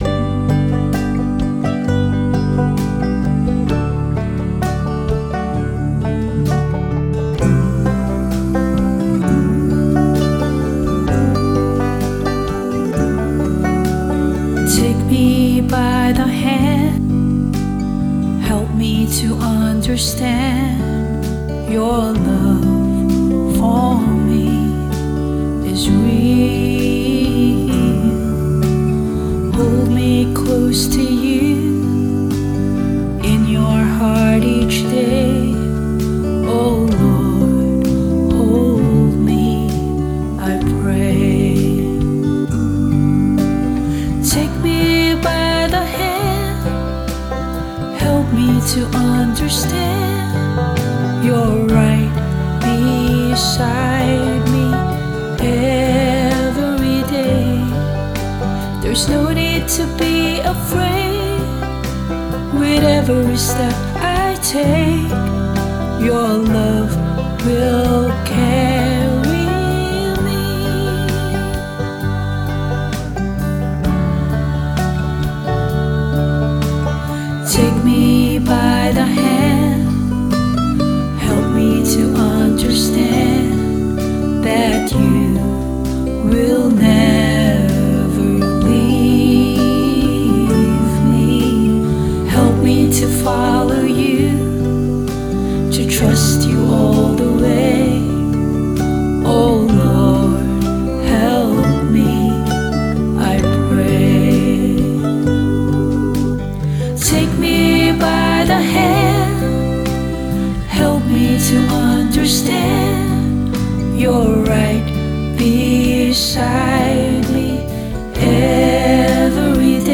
Vocals and Band-In-A-Box arrangement